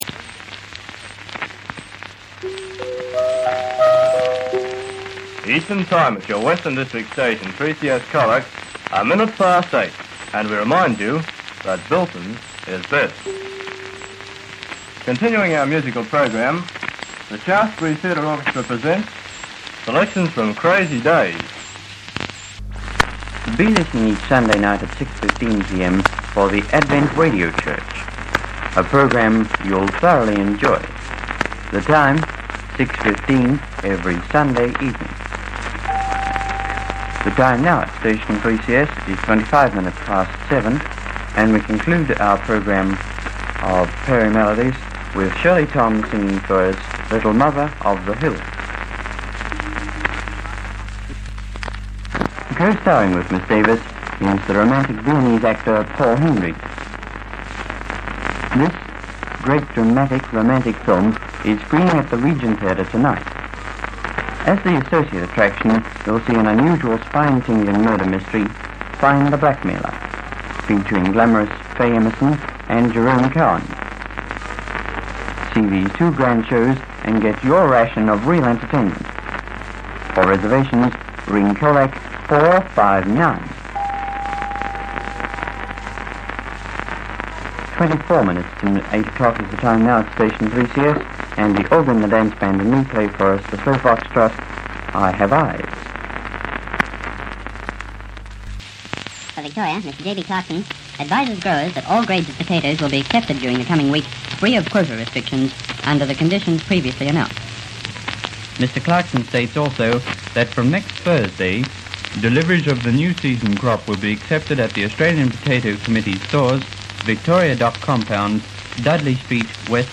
Most of this is sourced from various analogue sources including acetate discs real to real tapes and the humble cassette.
3CS Colac Wartime Off air recordings of 3CS during the War years.